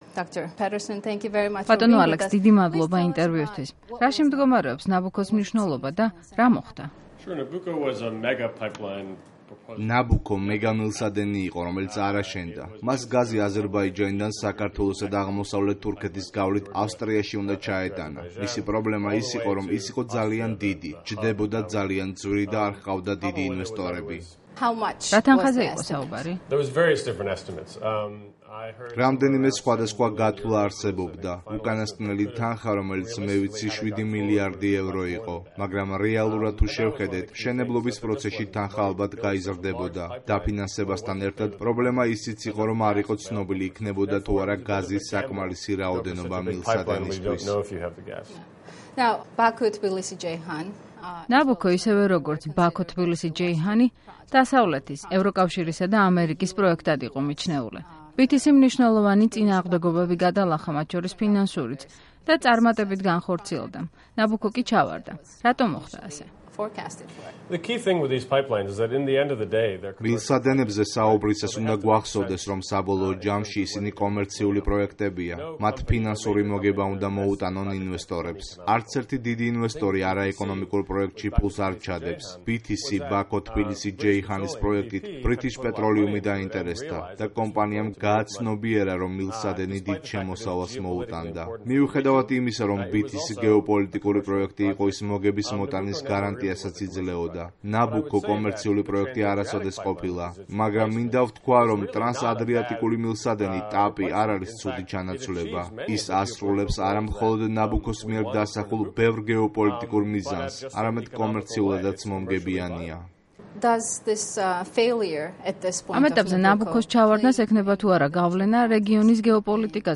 გთავაზობთ ამ ინტერვიუს ჩანაწერს.